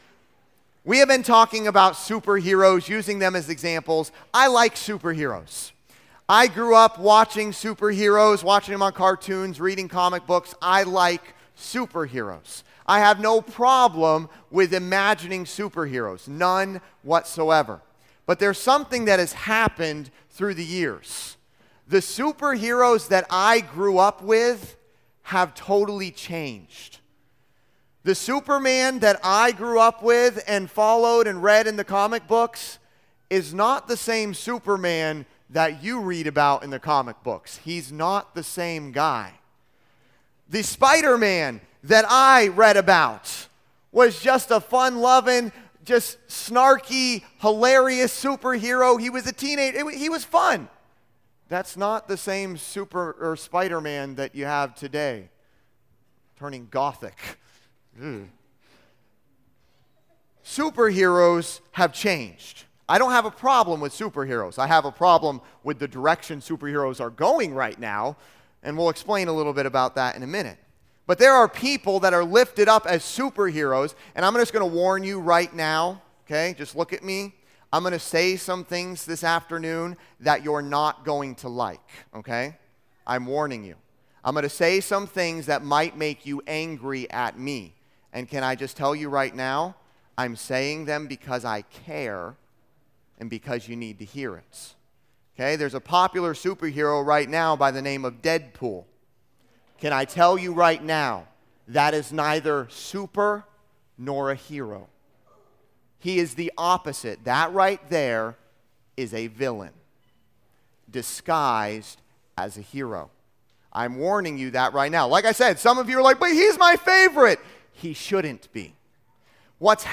Listen to Message
Service Type: Junior Camp